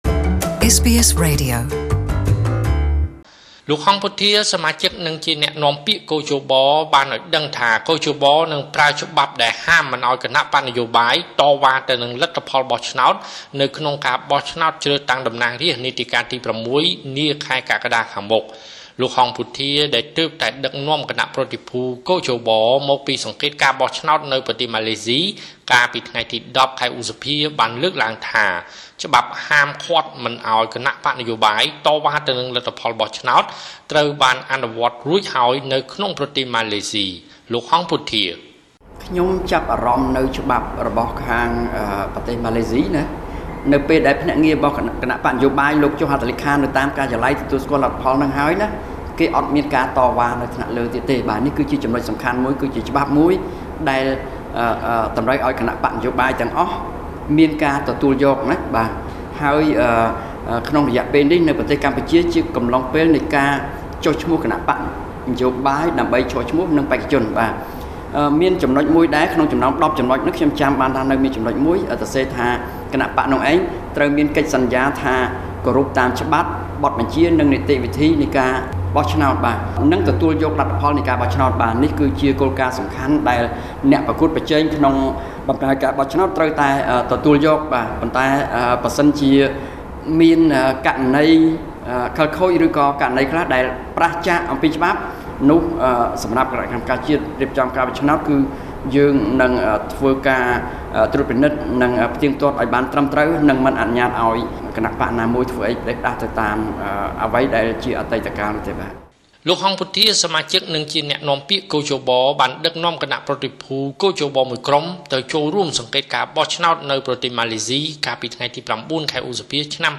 ( សំឡេងលោក ហង្ស ពុទ្ធា )